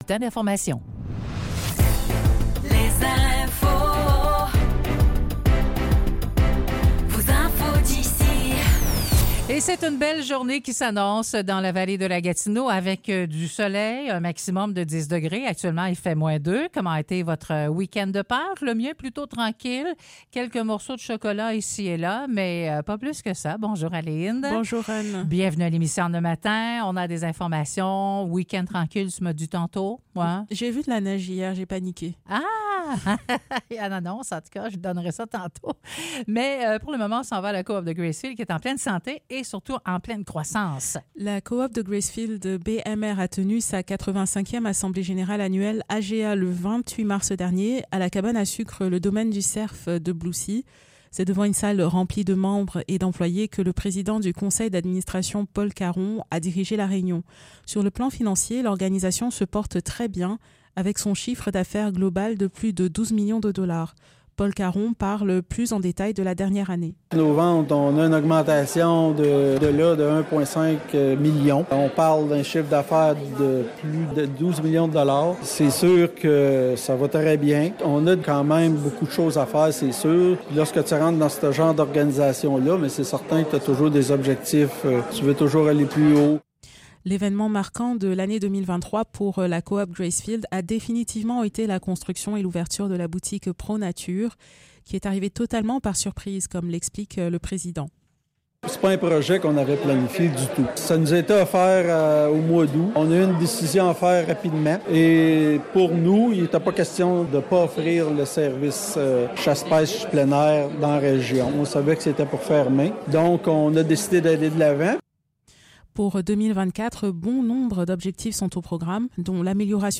Nouvelles locales - 2 avril 2024 - 9 h